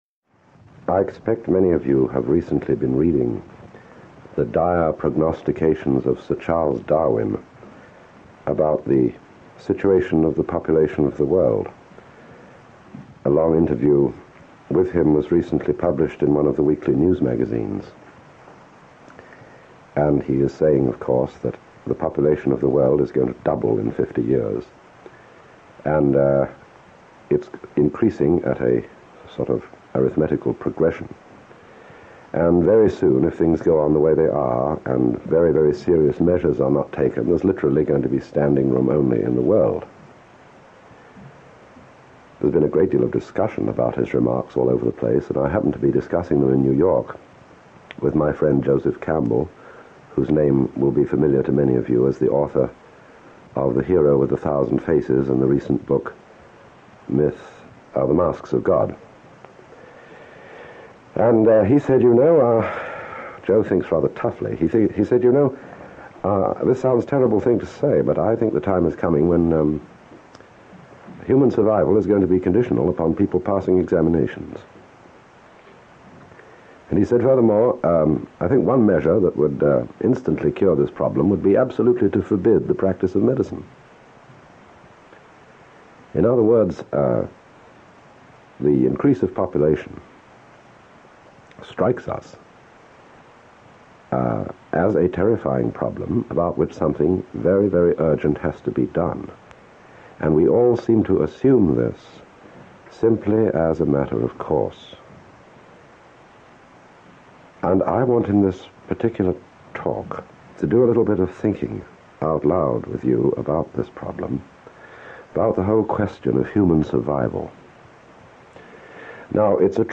Alan Watts – Early Radio Talks – 03 – Bang or Whimper